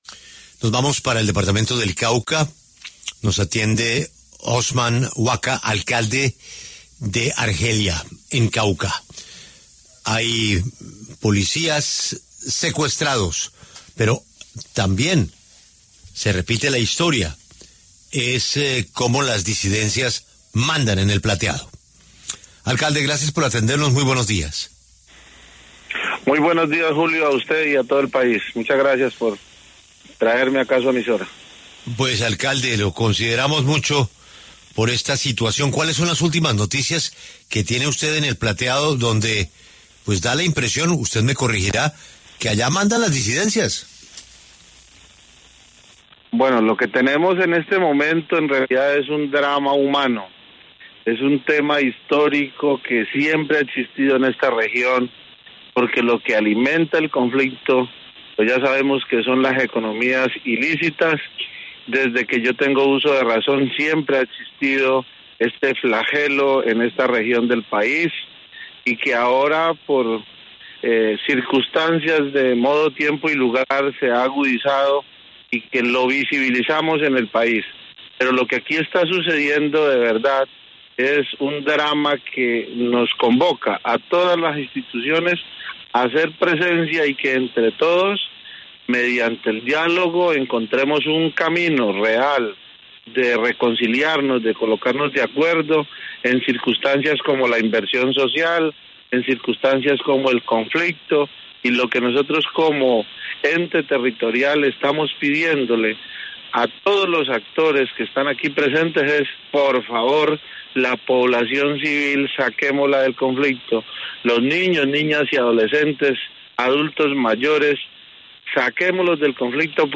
Osman Guaca, el alcalde de Argelia (Cauca), en entrevista en La W, rechazó los recientes eventos violentos registrados en su región, incluidos la asonada contra las tropas del Ejército en la vereda La Hacienda y el secuestro de 28 policías y un militar por parte de la comunidad.